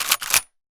gun_shotgun_cock_03.wav